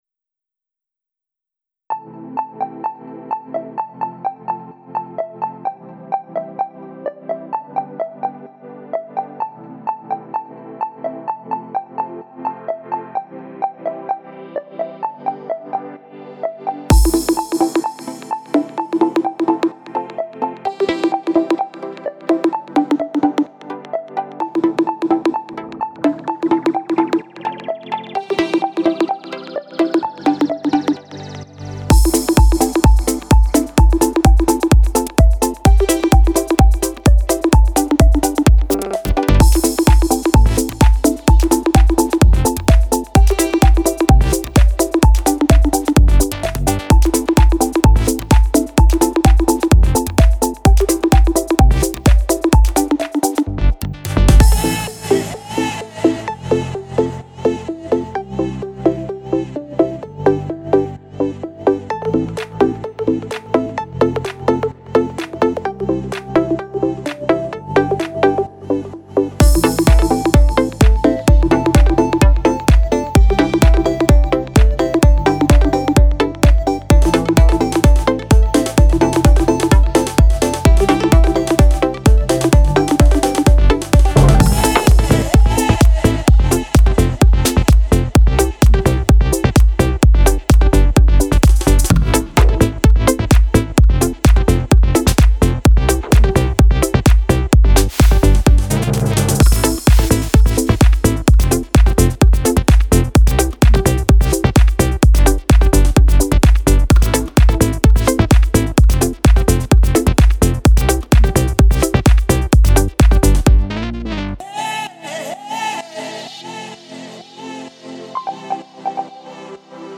Что-то в нац. чукотском стиле). BPM: 128 Длительность:3 мин 50 сек. Стиль: Диско или электроника.